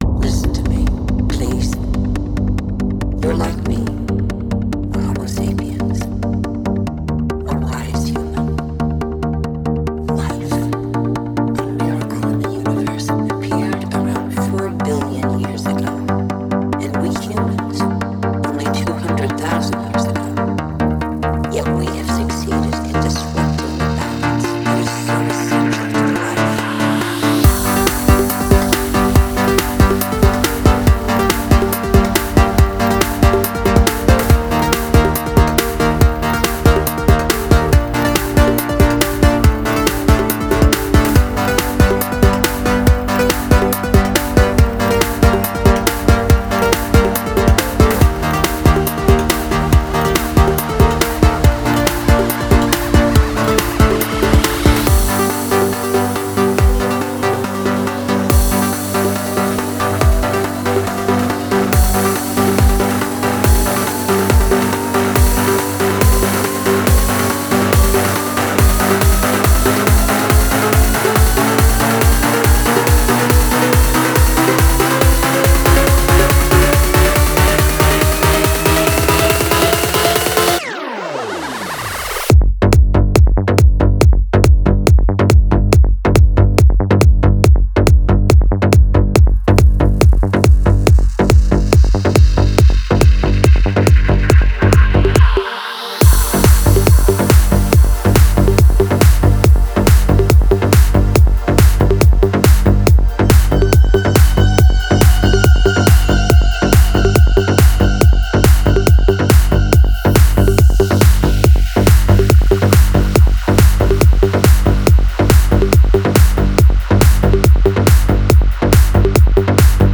Style: Pop, Electro, FullOn
Quality: 320 kbps / Stereo